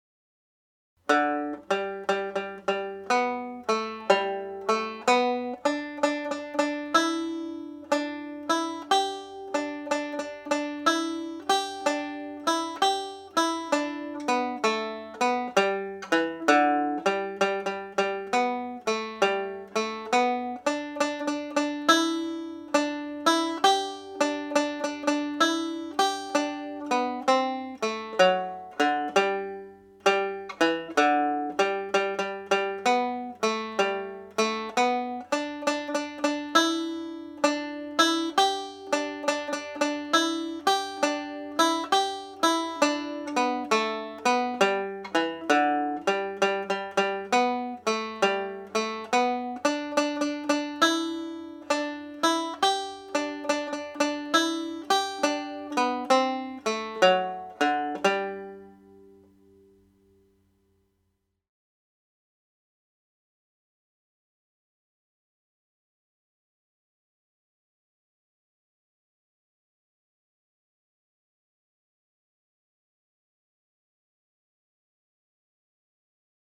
complete tune played slowly with triplets added